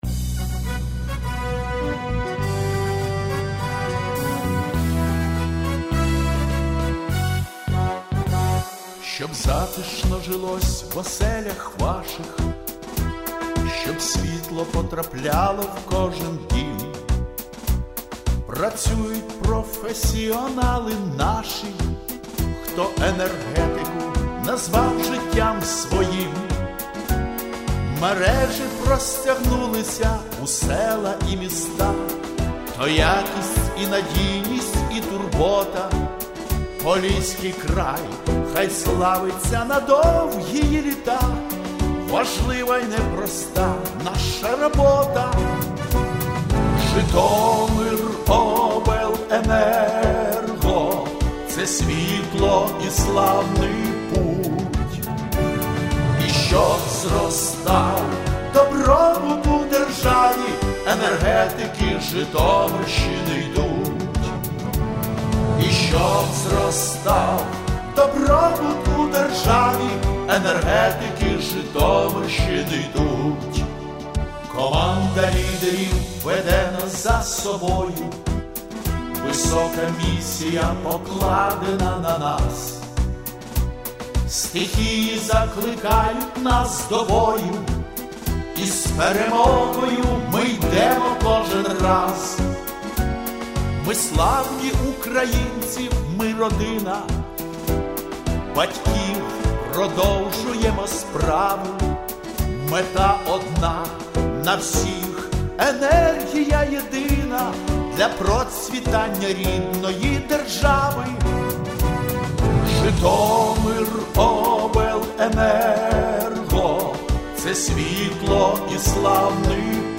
Гімн ”Житомиробленерго”